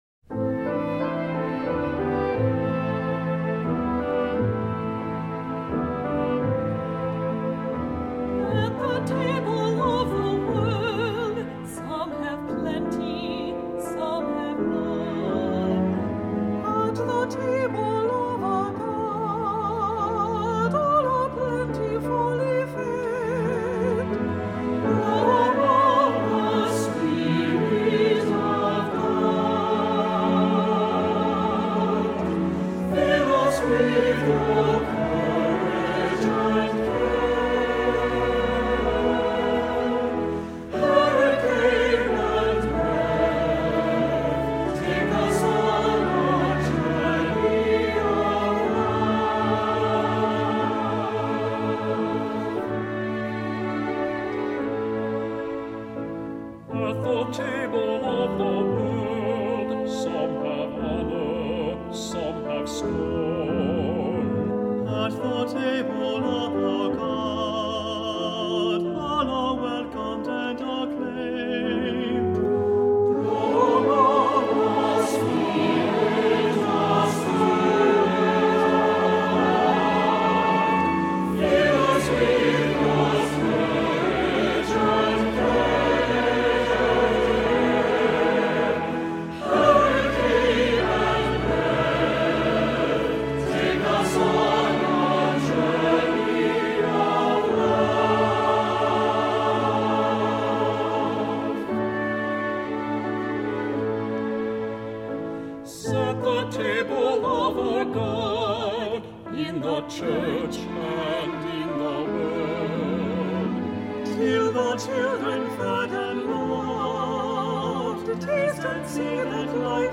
Voicing: SATB; 2 Cantors or Solos; Assembly